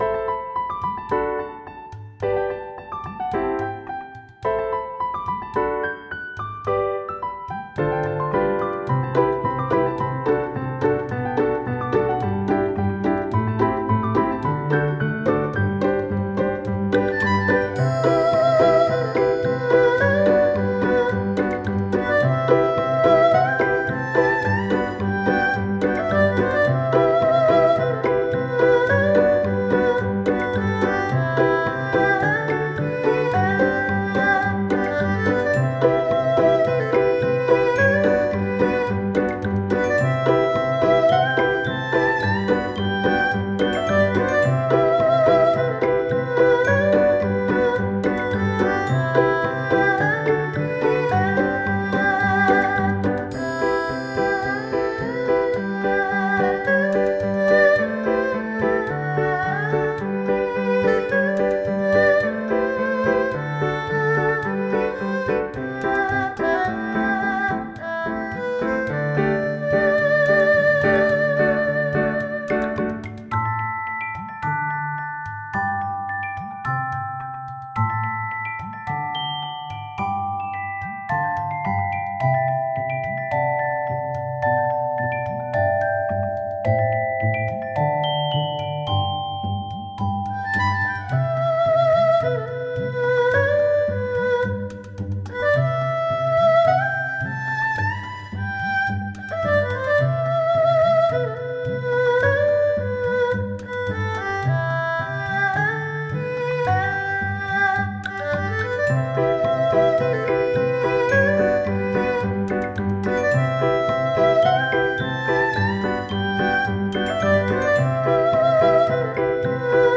フリーBGM素材- おりえんたるな感じ。